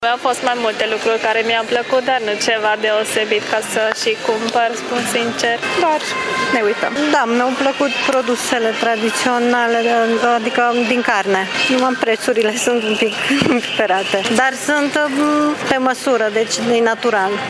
Târgumureșenii au vizitat standurile producătorilor mai mult din curiozitate, iar unii s-au oprit să cumpere: